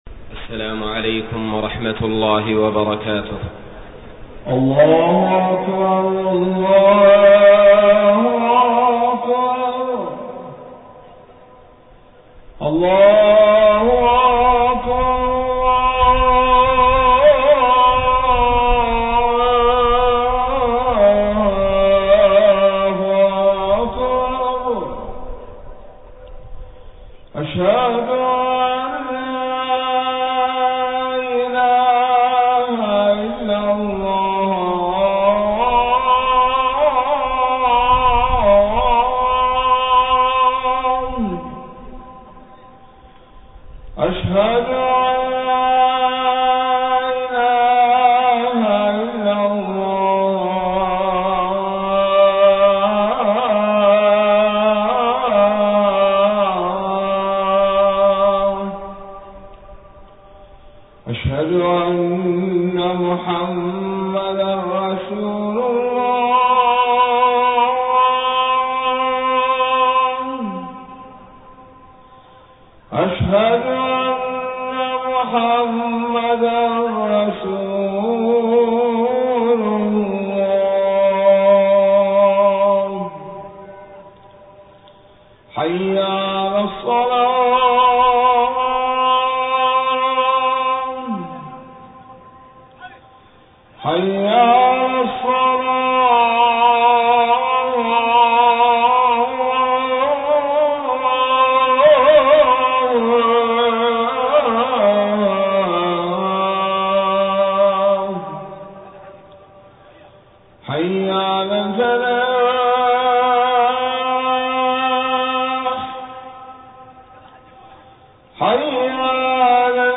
خطبة الجمعة 5 ربيع الأول 1431هـ > خطب الحرم المكي عام 1431 🕋 > خطب الحرم المكي 🕋 > المزيد - تلاوات الحرمين